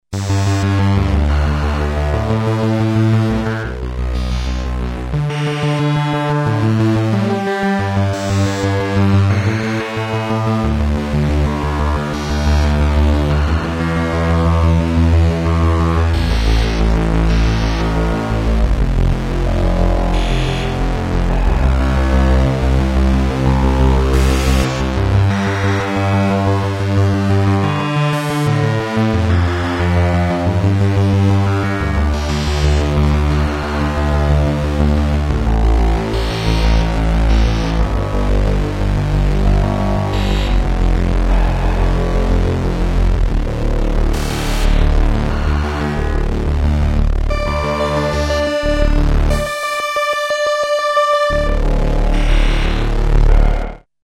Digital Wave Synthesizer (1986)
organic arpeggio
demo arpeggio filter
demo organic arpeggio II